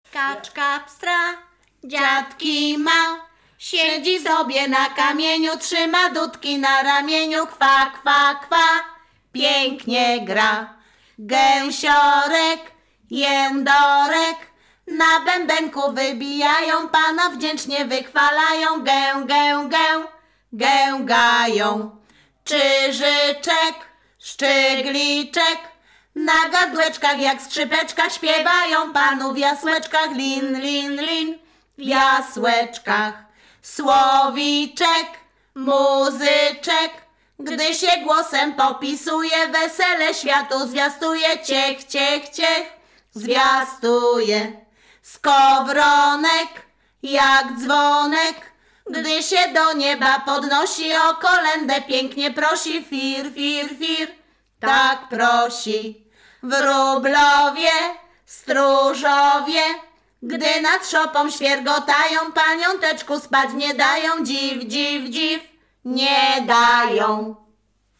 Śpiewaczki z Chojnego
województwo łódzkie, powiat sieradzki, gmina Sieradz, wieś Chojne
Kolęda